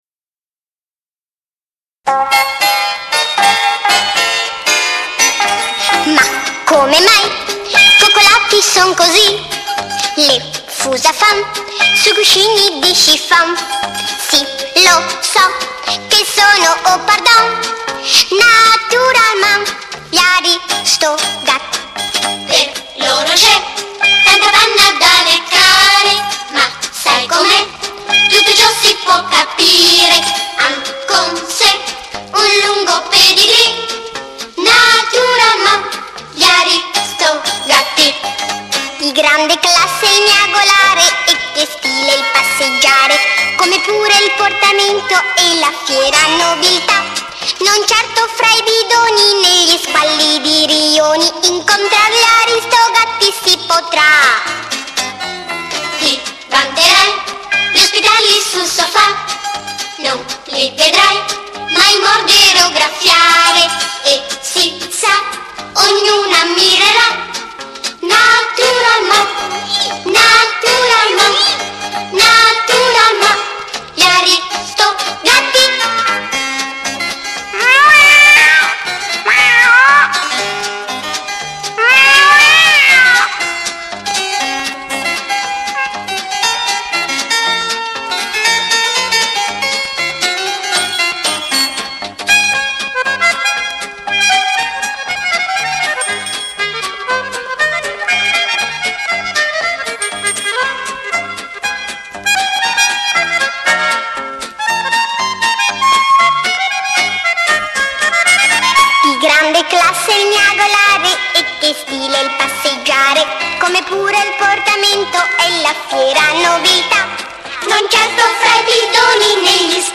CORO E ORCHESTRA